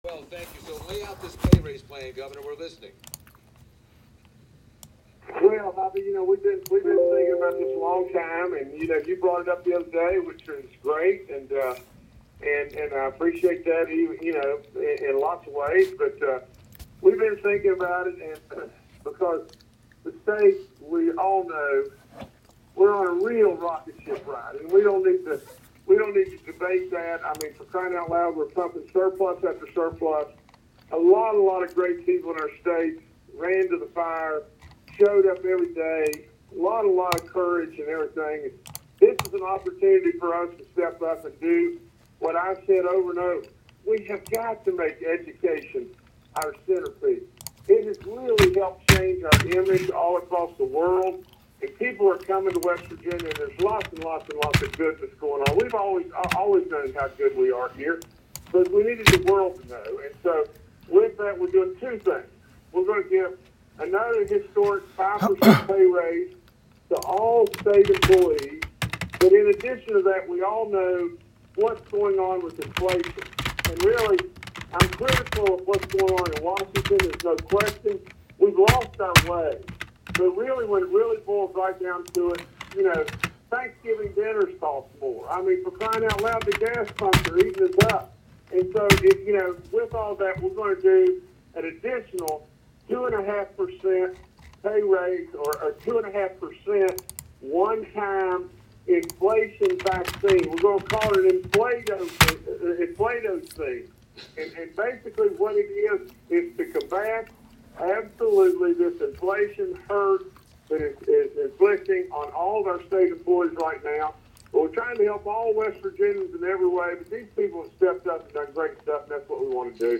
Justice appeared on MetroNews’ “Talkline” Thursday morning, and here is a recording (may not work for everybody).